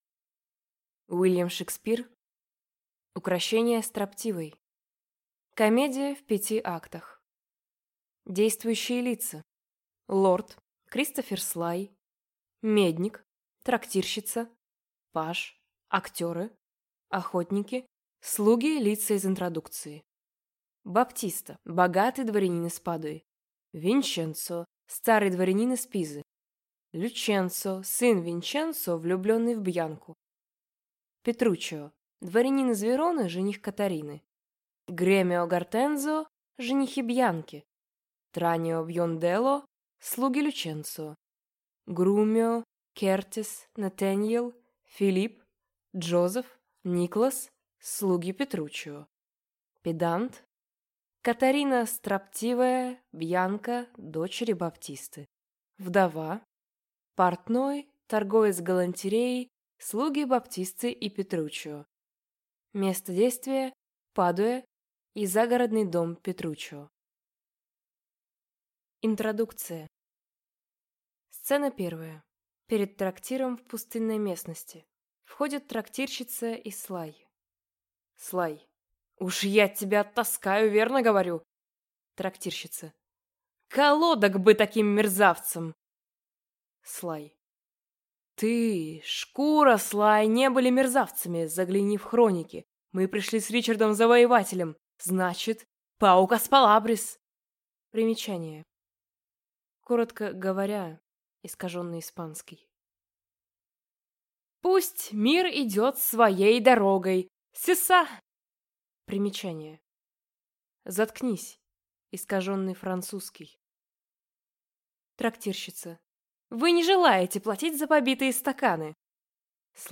Аудиокнига Укрощение строптивой | Библиотека аудиокниг